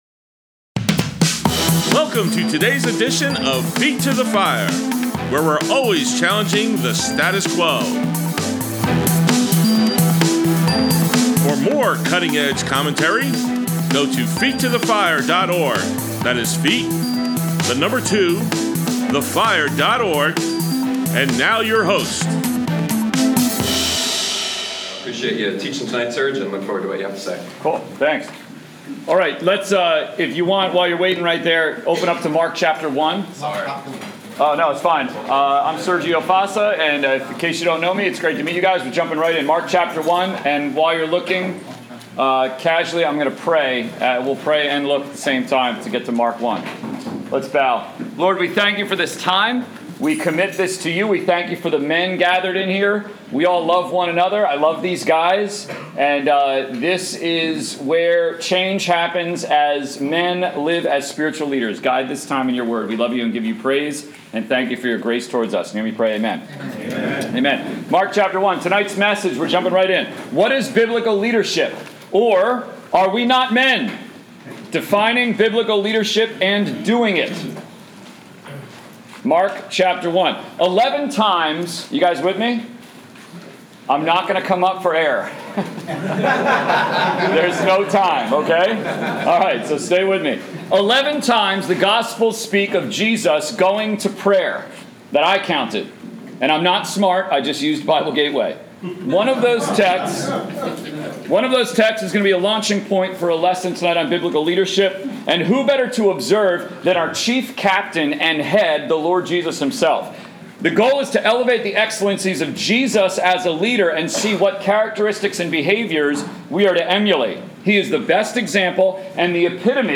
Saturday Sermon: What is Biblical Leadership